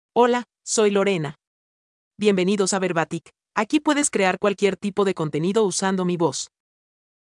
FemaleSpanish (El Salvador)
Voice sample
Female
Spanish (El Salvador)